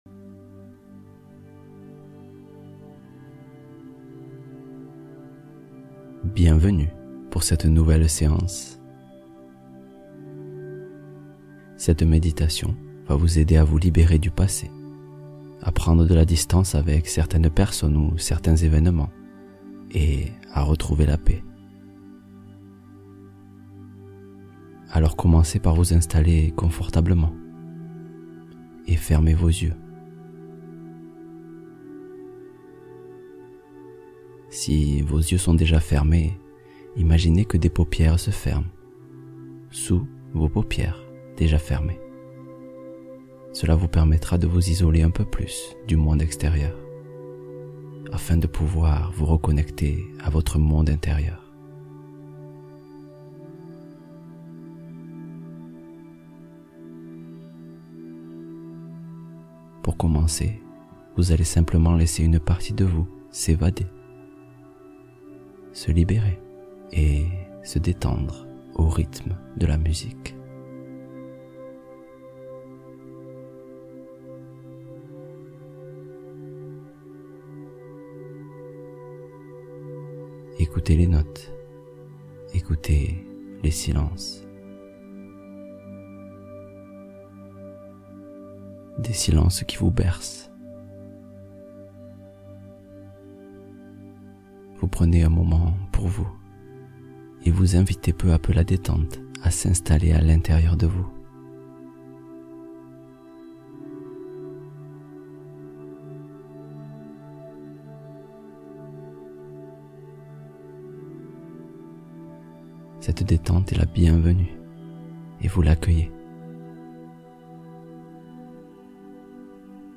S’aimer pleinement : méditation guidée pour réveiller l’amour de soi